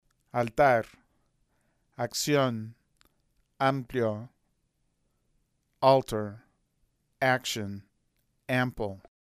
El ataque vocálico creciente y el ataque vocálico abrupto.